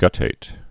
(gŭtāt) also gut·tat·ed (-ātĭd)